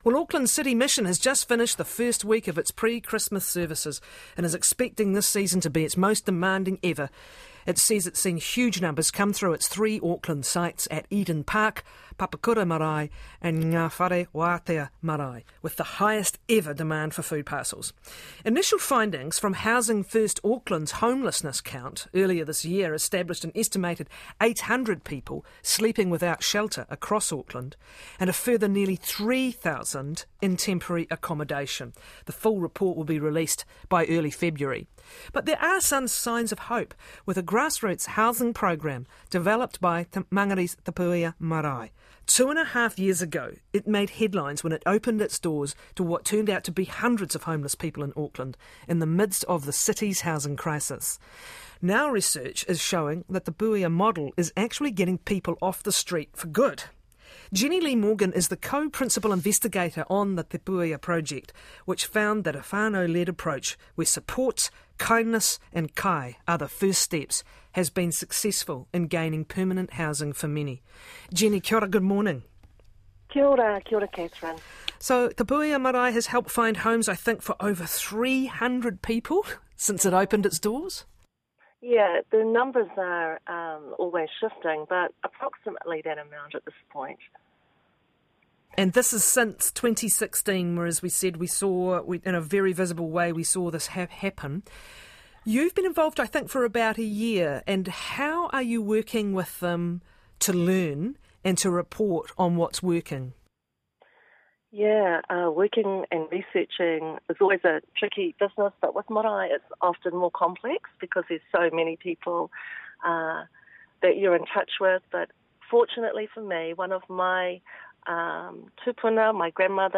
Source RNZ, Nine to Noon